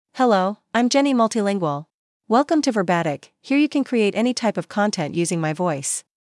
Jenny MultilingualFemale English AI voice
Jenny Multilingual is a female AI voice for English (United States).
Voice sample
Listen to Jenny Multilingual's female English voice.
Jenny Multilingual delivers clear pronunciation with authentic United States English intonation, making your content sound professionally produced.